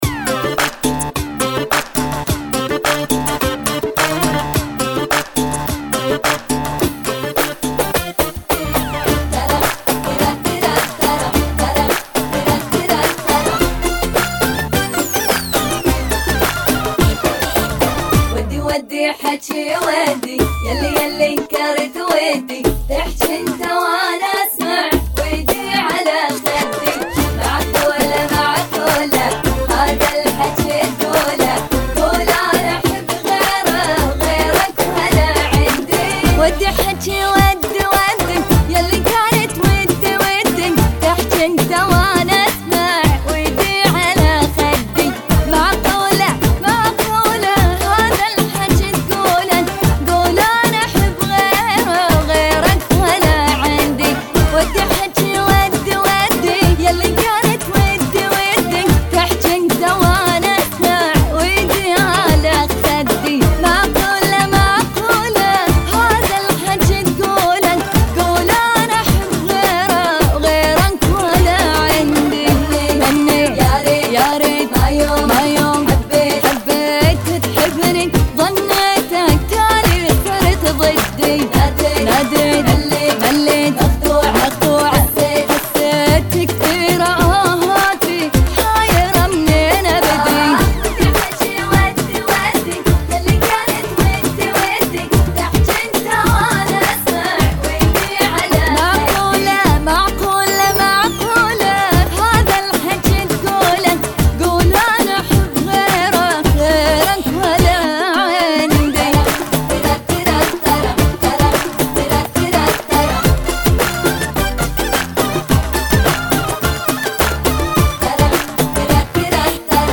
(106 BPM)